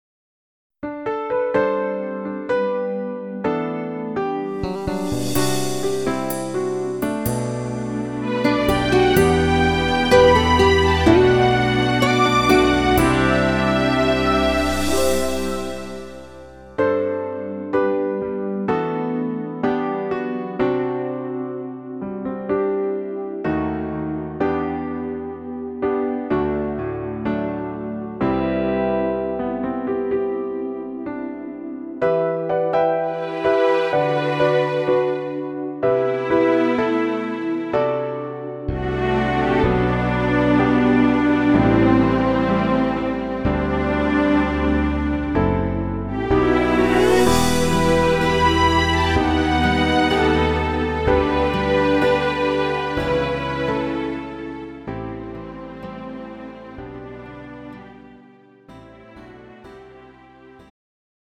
음정 원키
장르 가요 구분 Lite MR
Lite MR은 저렴한 가격에 간단한 연습이나 취미용으로 활용할 수 있는 가벼운 반주입니다.